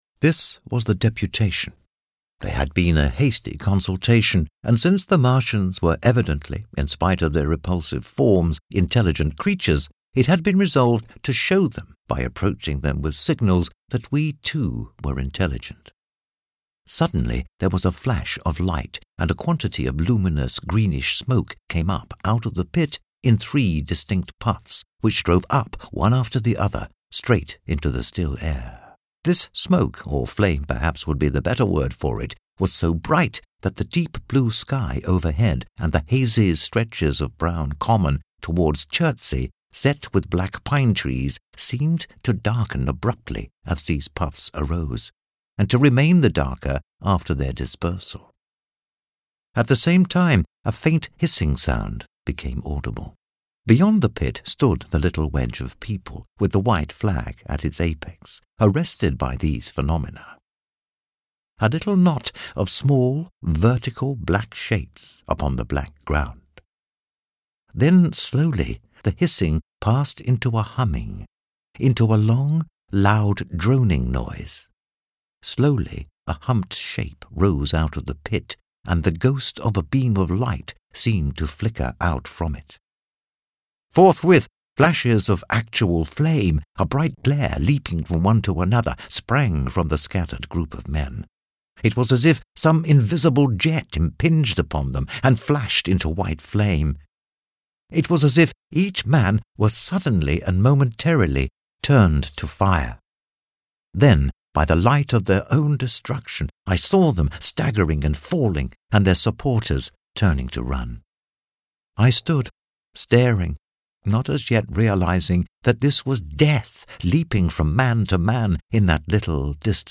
Story Teller
The narration style here is suited to story telling in a variety of genres.
Accent: English